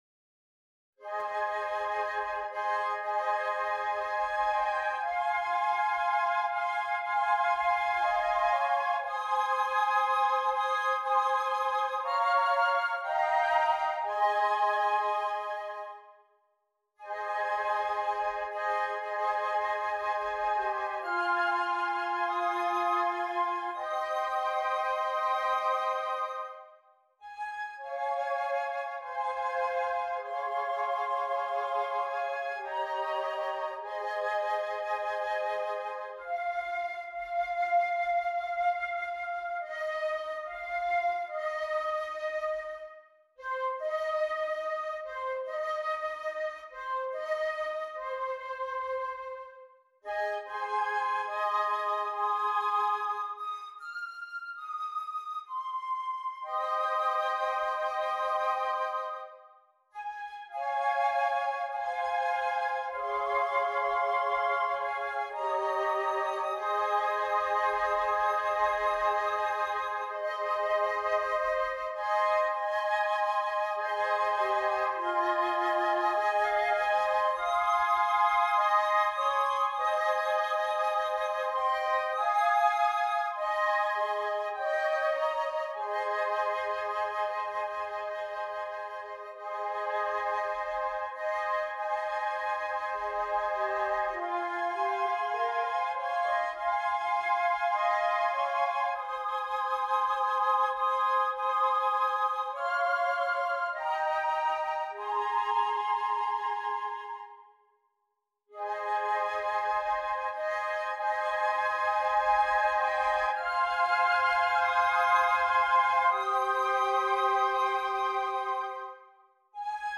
5 Flutes
Traditional